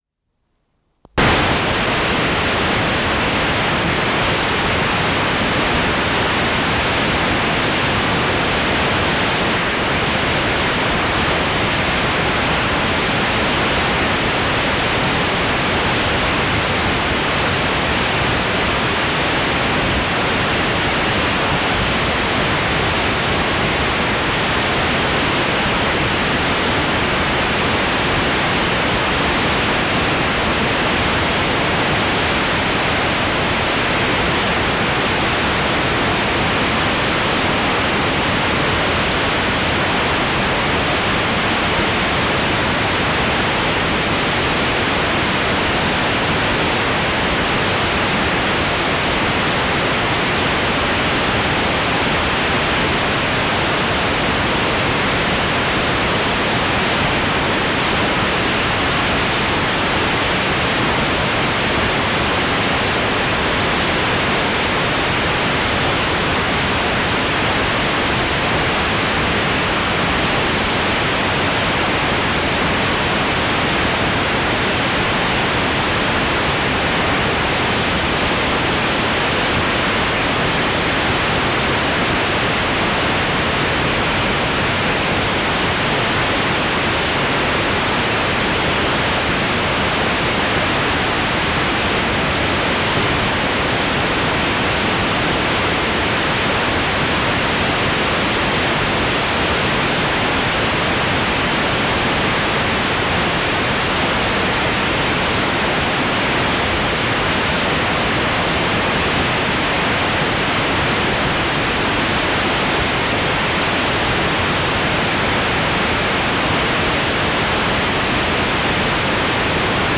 (tape rip)